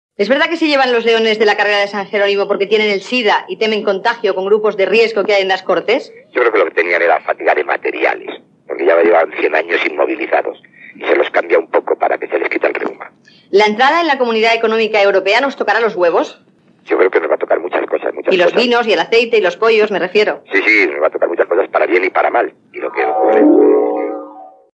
Barcelona directo: entrevista Pablo Castellano - Radio Barcelona